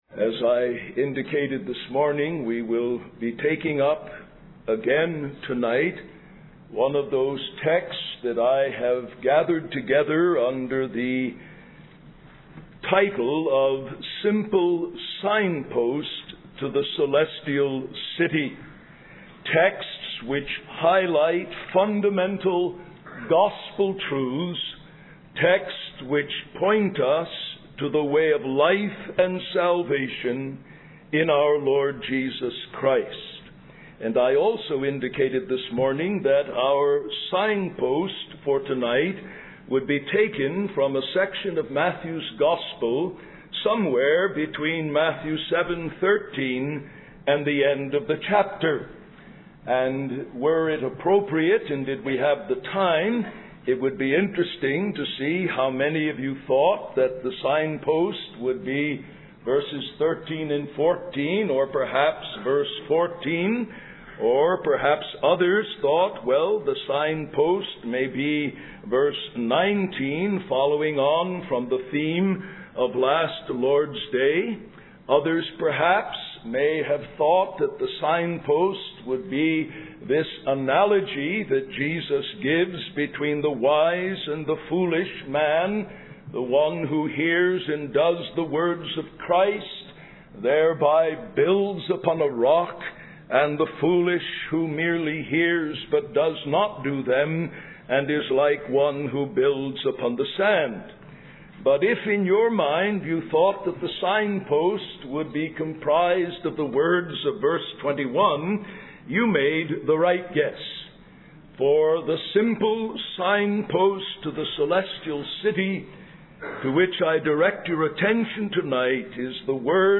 In this sermon, the speaker focuses on a signpost from Matthew's gospel that serves as a guide to the Celestial City.